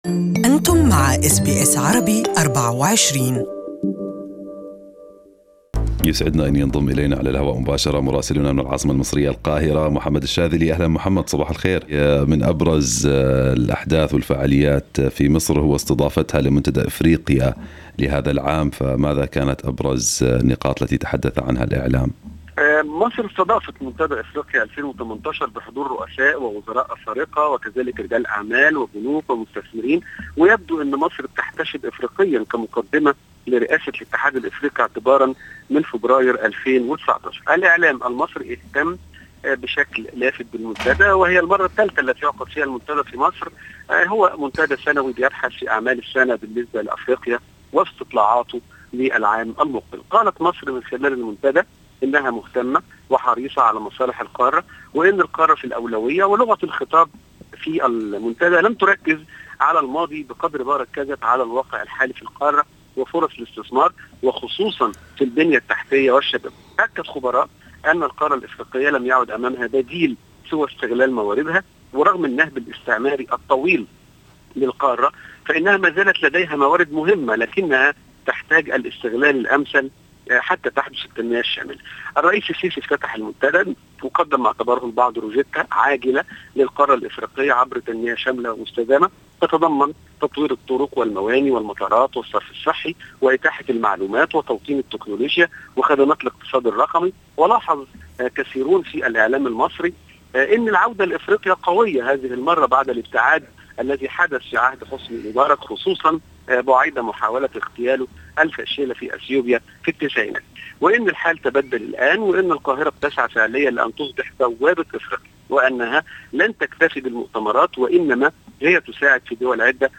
Our correspondent in Cairo has the details